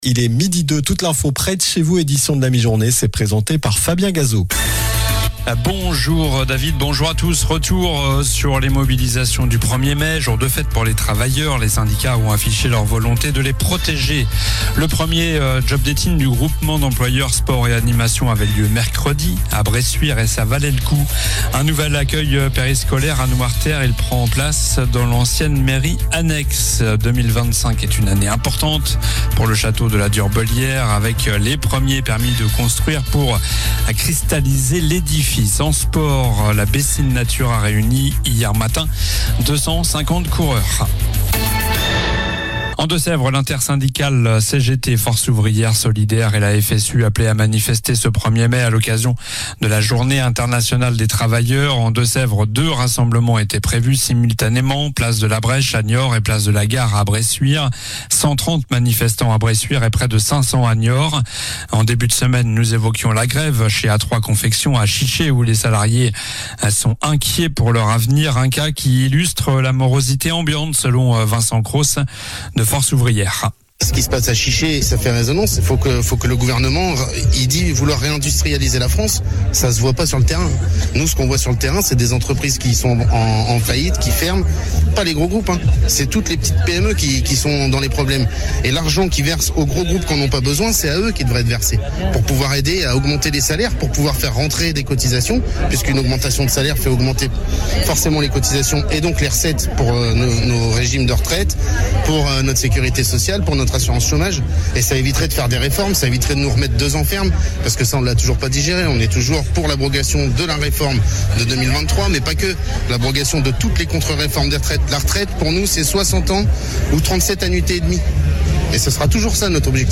Journal du vendredi 02 Mai (midi)